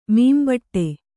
♪ mīmbaṭṭe